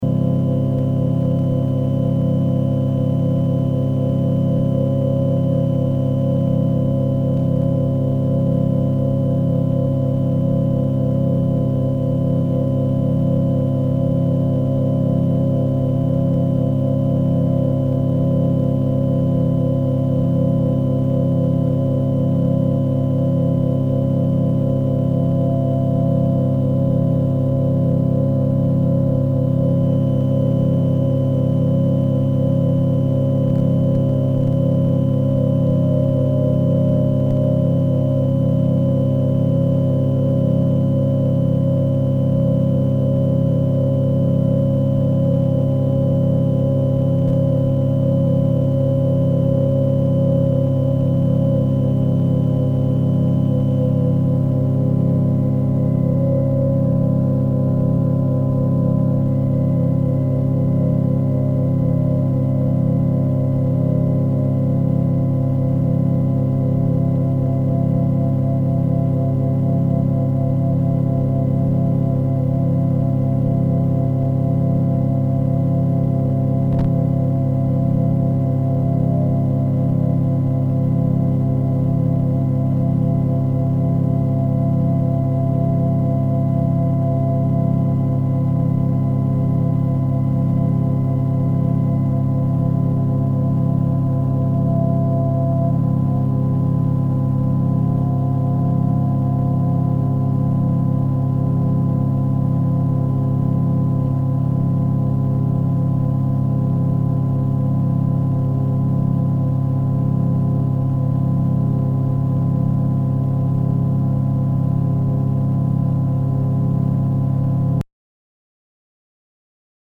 drone work for a Buchla synthesizer while at NYU in 1970.
It’s just enormous.